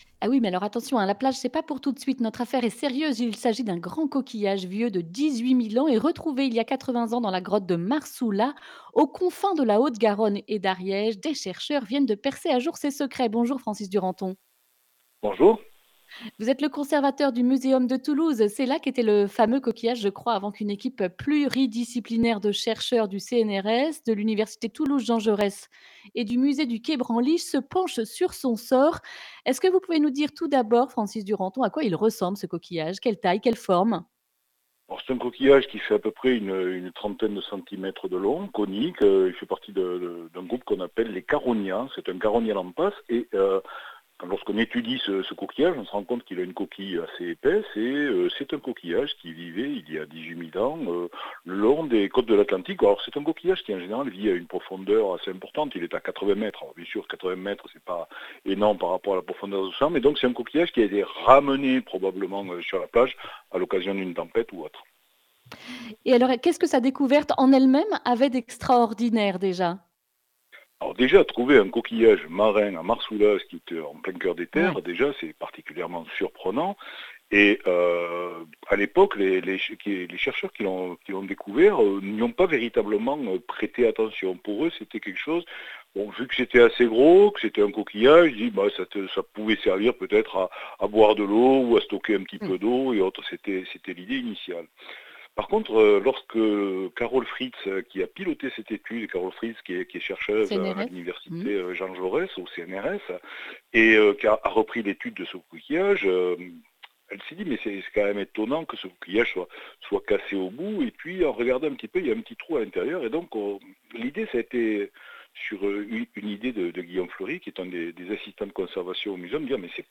mardi 16 février 2021 Le grand entretien Durée 11 min